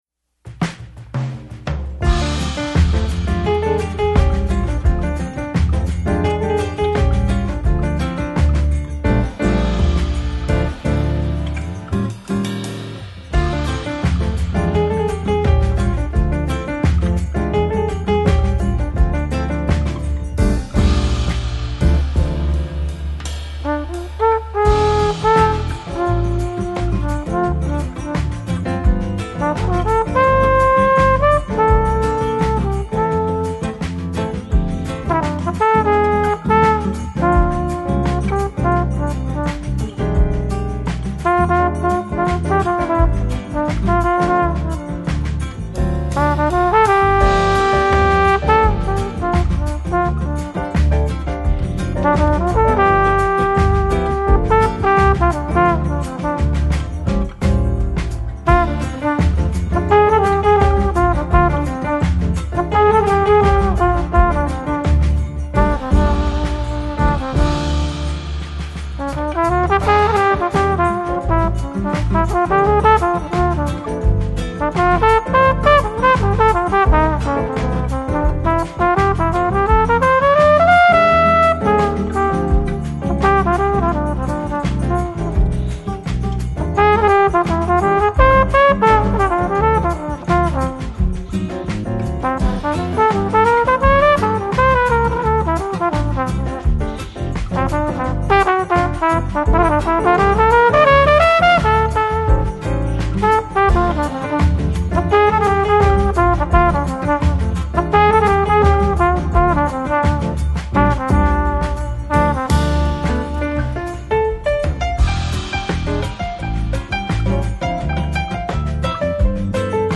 Жанр: Instrumental, Easy listening, Lounge